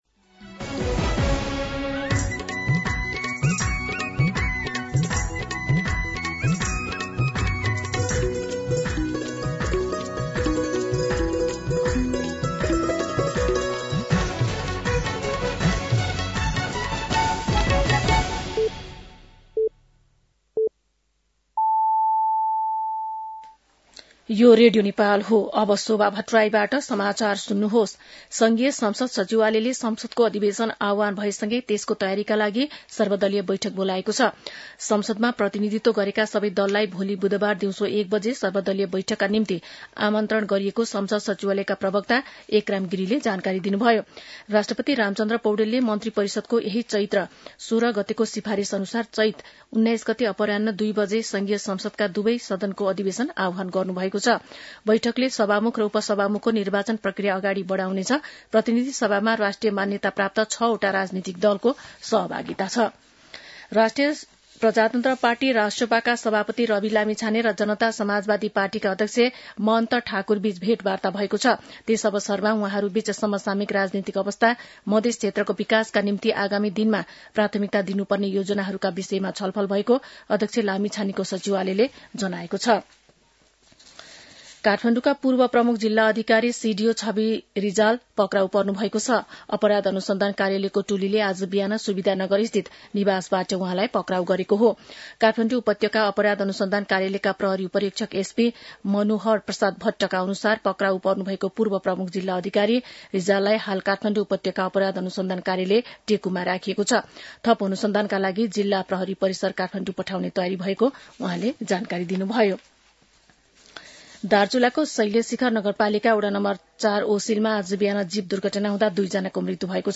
दिउँसो १ बजेको नेपाली समाचार : १७ चैत , २०८२